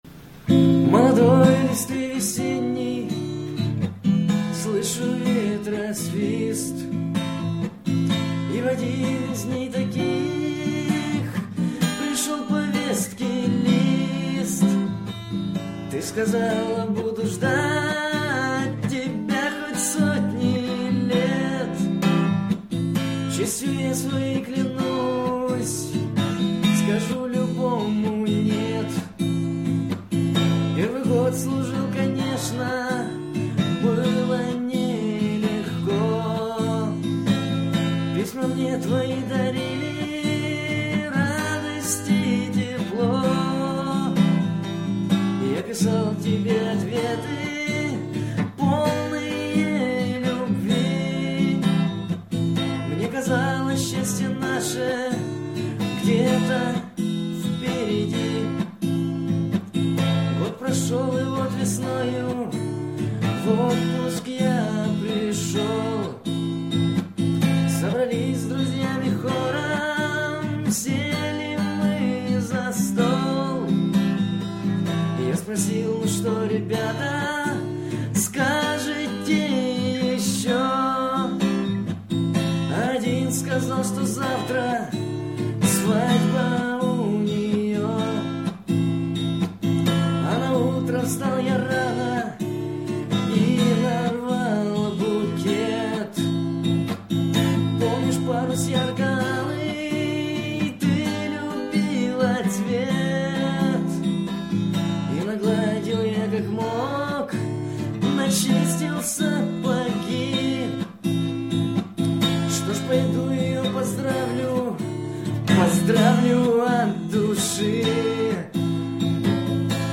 Армейские и дворовые песни под гитару
Песня грустная, а голос то какой..... Замечательно!